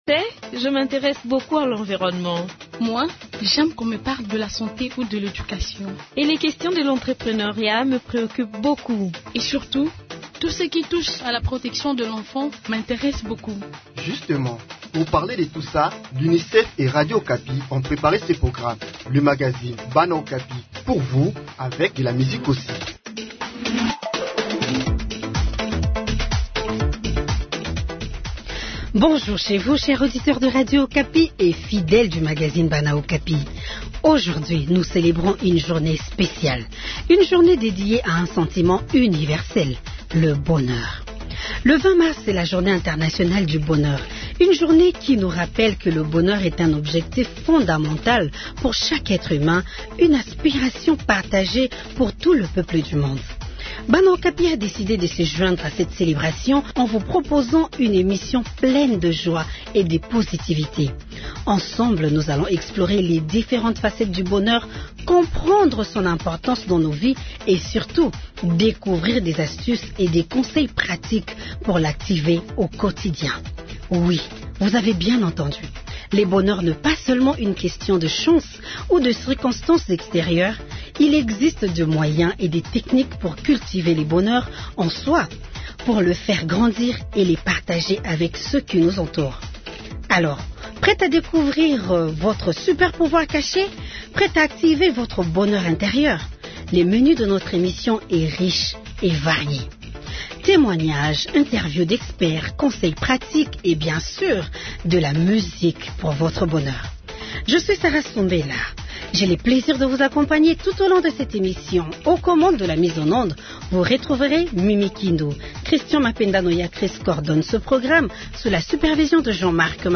Bana Okapi a décidé de se joindre à cette célébration en vous proposant une émission pleine de joie et de positivité. Ensemble, nous allons explorer les différentes facettes du bonheur, comprendre son importance dans nos vies et surtout, découvrir des astuces et des conseils pratiques pour l'activer au quotidien.